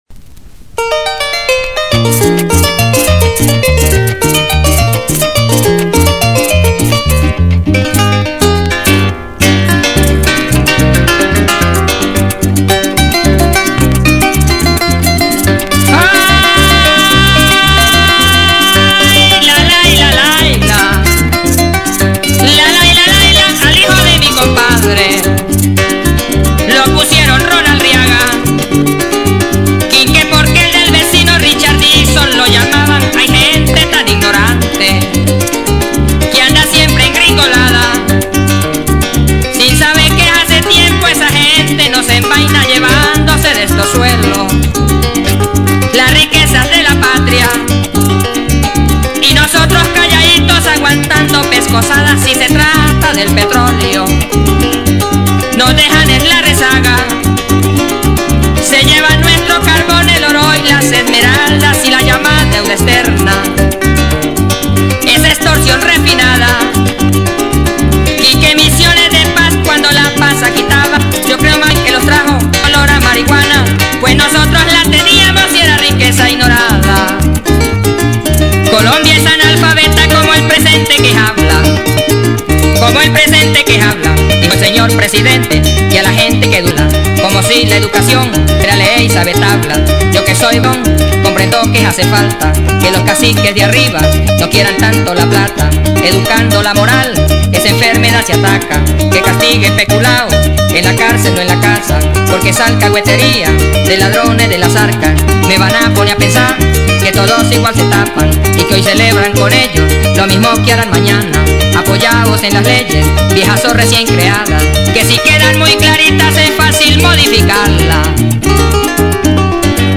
Ritmo: Pajarillo – Chipola.